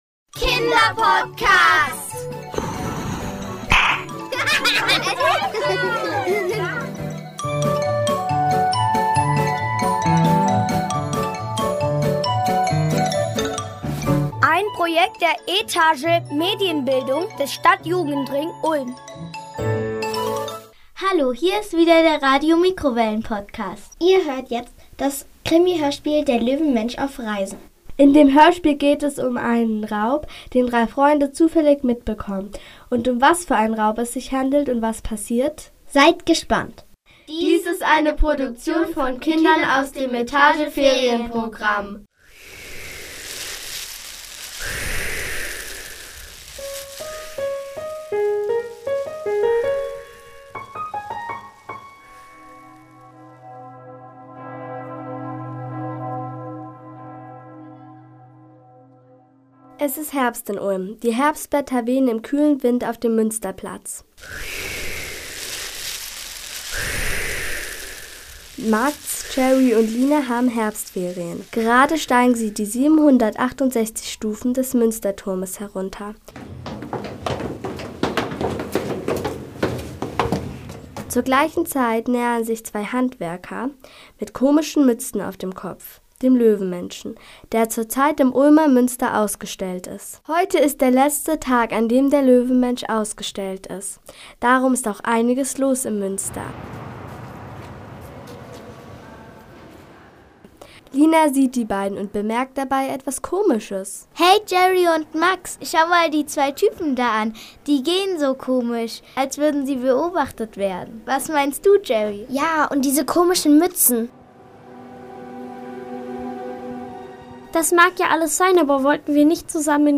Der_Loewenmensch_auf_Reisen_ein_Kriminalhoerspiel.mp3